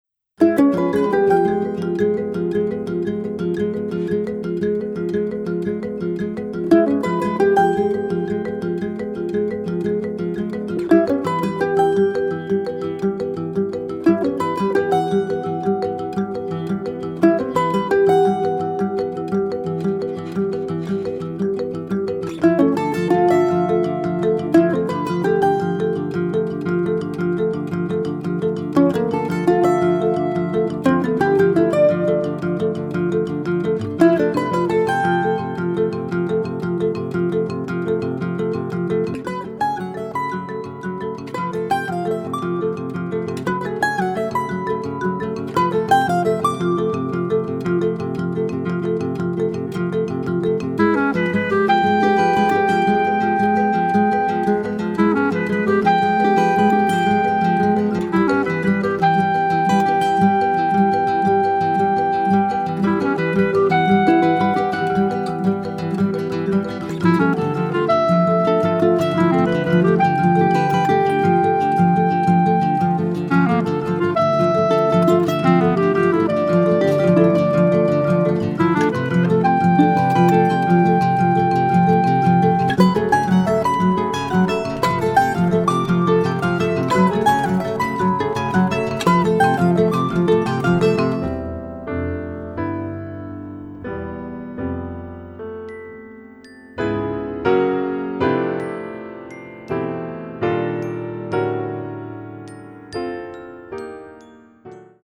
全体を貫く浮遊感も心地良い大充実の1枚です！